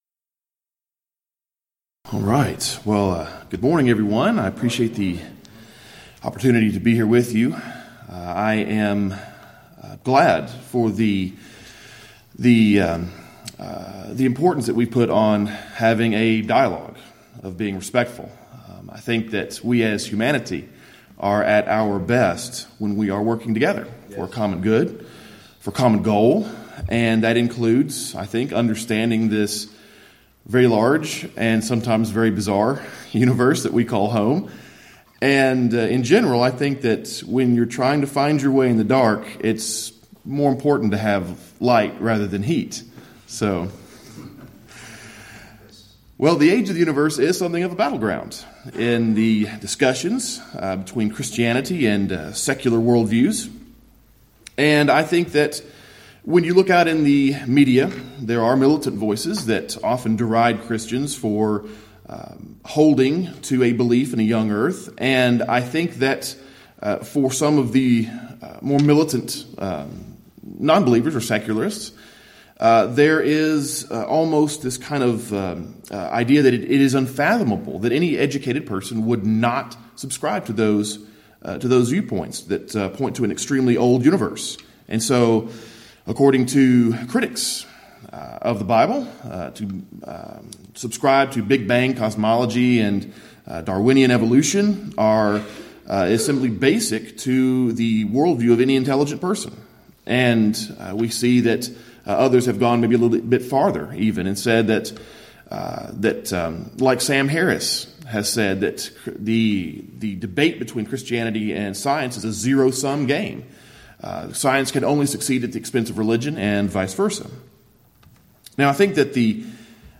Alternate File Link File Details: Series: Shenandoah Lectures Event: 27th Annual Shenandoah Lectures Theme/Title: The Evolution of Enlightenment: Can Science and Religion Co-Exist?